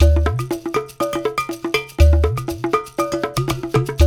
120 -UDU 04L.wav